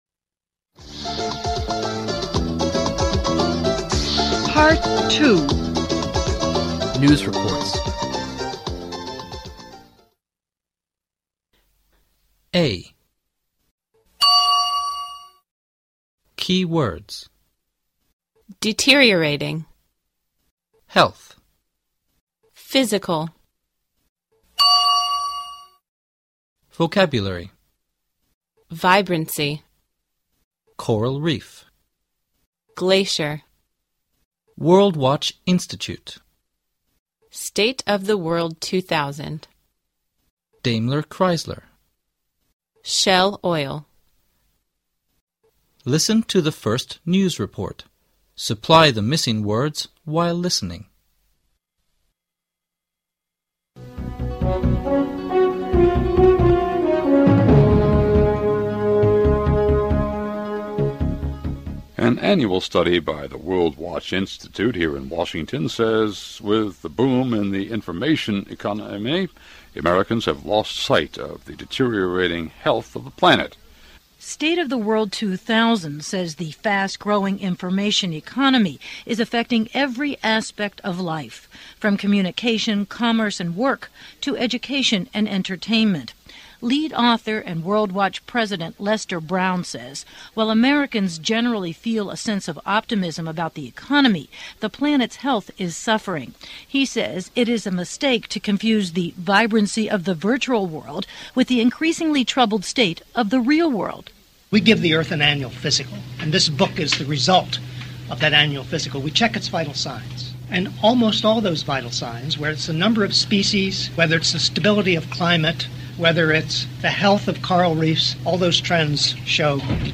Part 2. News reports.